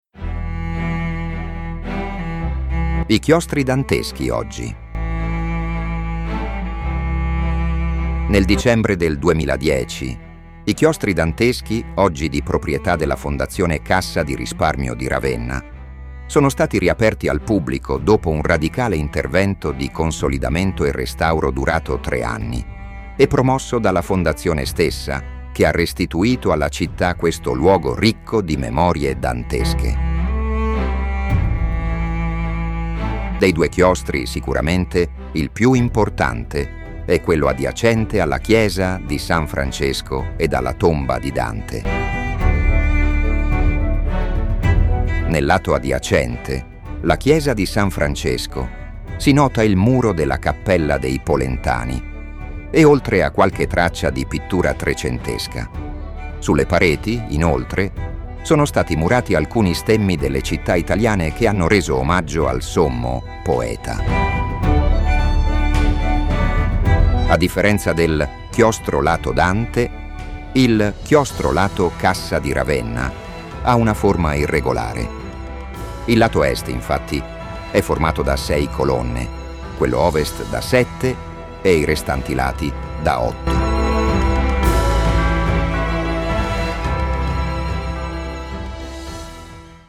Audio_Guida
Voce: AI
I_Chiostri_danteschi_oggi_audioguida.mp3